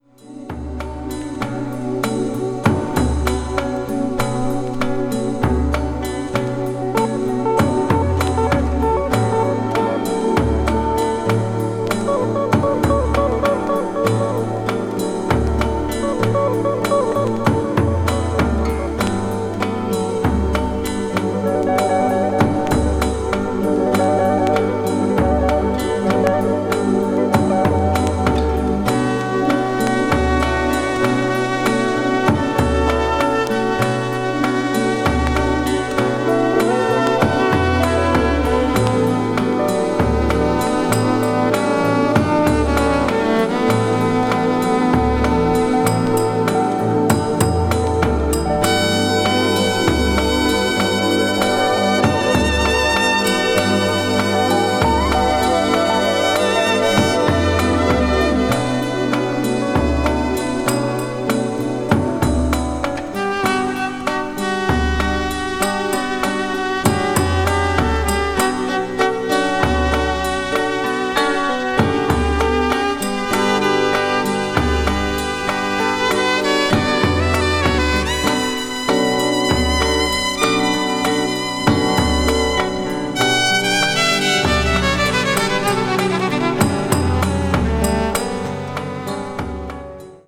A1前半に1cm程の薄いスリキズがあり、軽いプチノイズが10回ほど入ります。